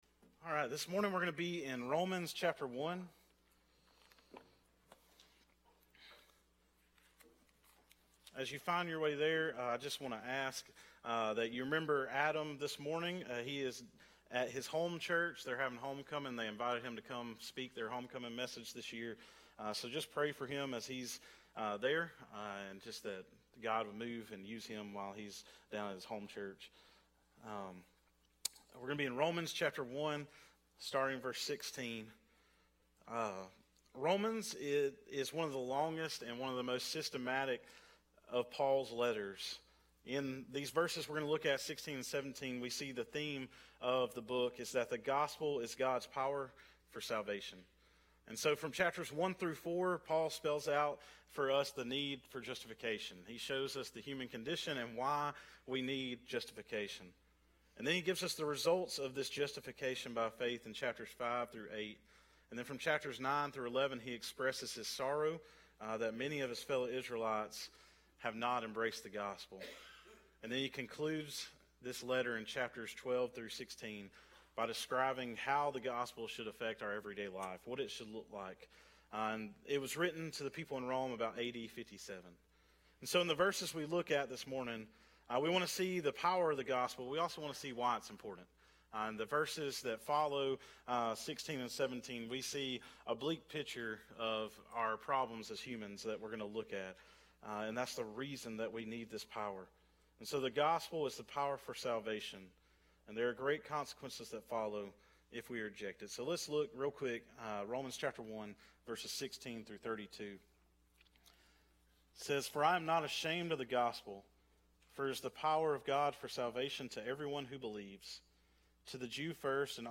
Sermons | Piney Grove Baptist Church